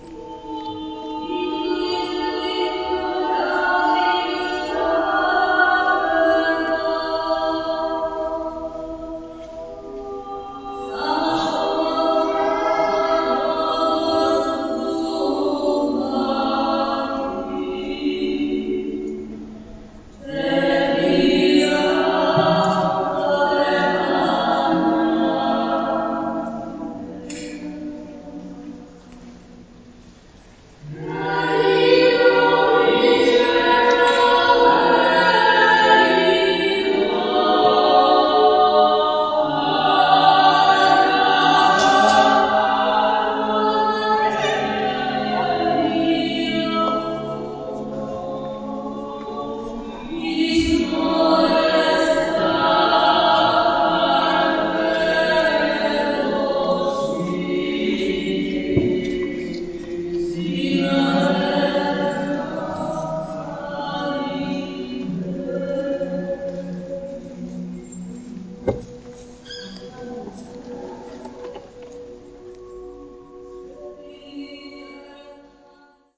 Direkt an der vielbefahrenen Via del Corso fand sich die Kirche San Marcello, in der man dem lauten Trubel entfliehen konnte. Vorne im Altarbereich standen vier Frauen, die offensichtlich für den Pfingst-Gottesdienst am nächsten Tag ihre Generalprobe absolviert haben. Mal spielte nur eine Violone, dann sangen die vier Frauen in beeindruckender Weise.
Kirchengesang.mp3